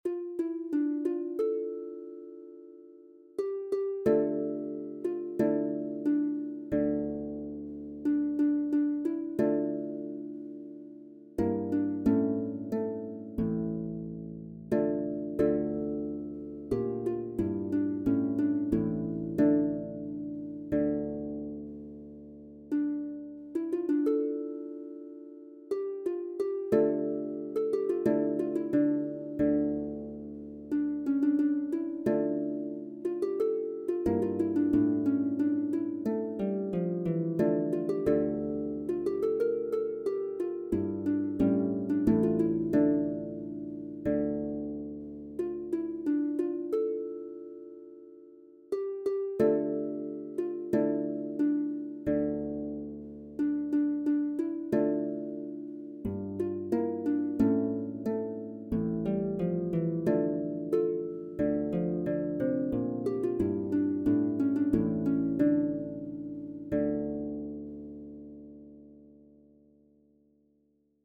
TORBAN/BAROQUE LUTE SOLOS